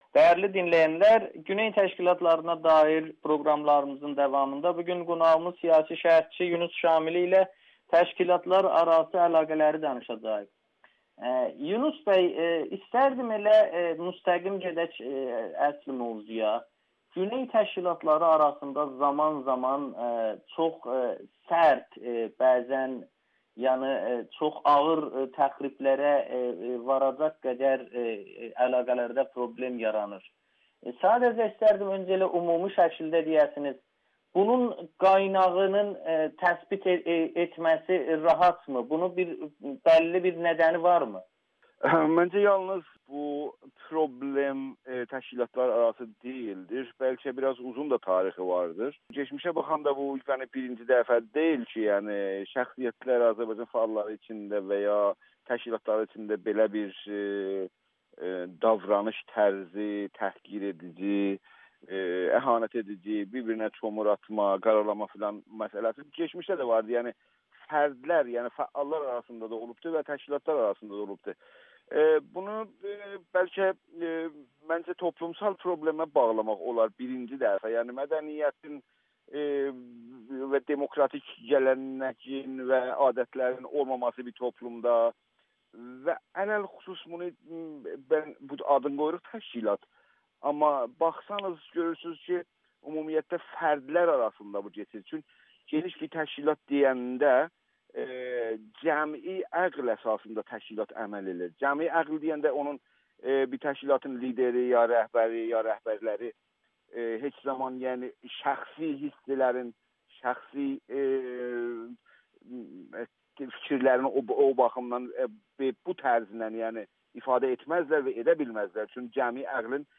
Bu təşkilatların fəaliyyəti kollektiv düşüncə əsasında deyil [Audio-Müsahibə]